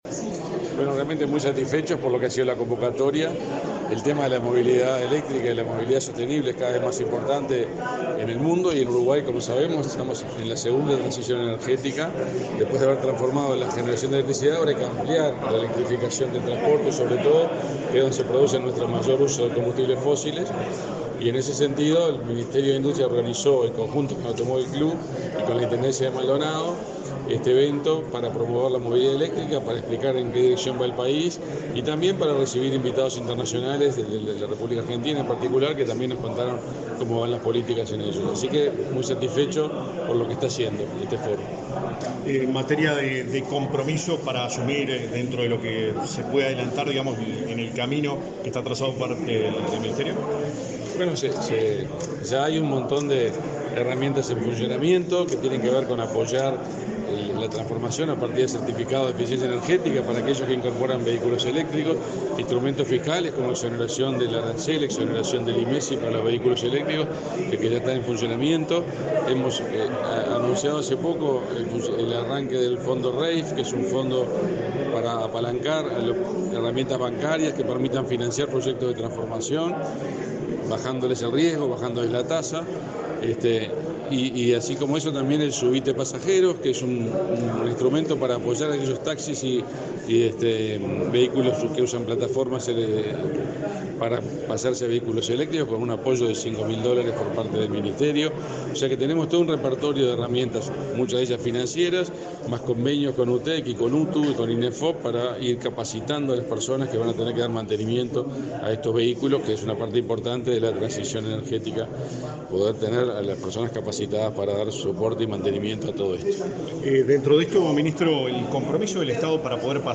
Declaraciones del ministro de Industria, Omar Paganini
Luego dialogó con la prensa.